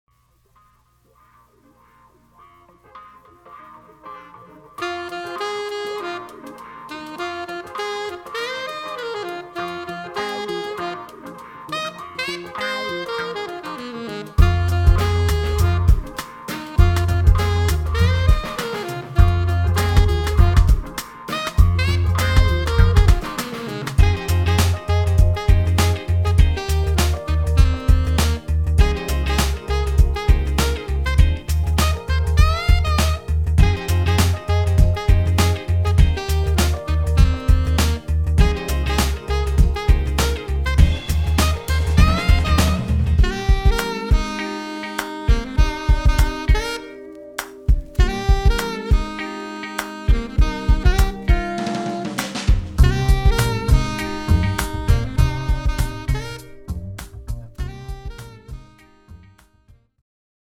Live Sounds